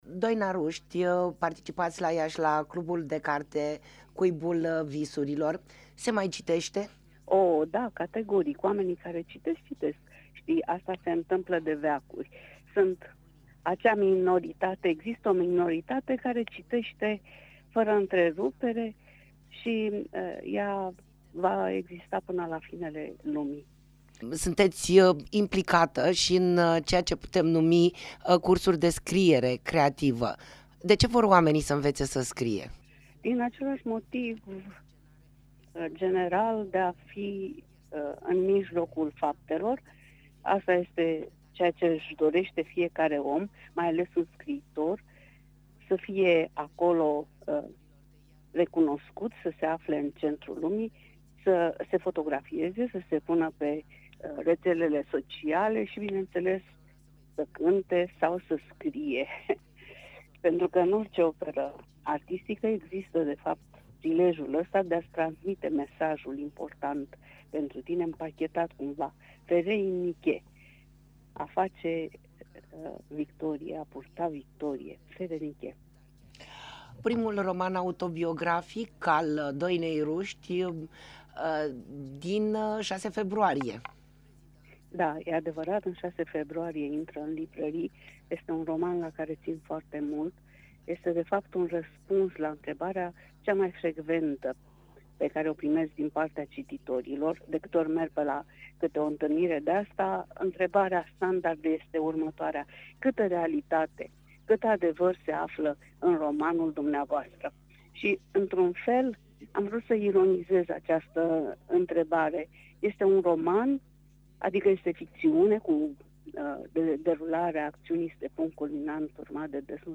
Radio HIT a provocat-o pe scriitoarea Doina Ruști la un scurt dialog.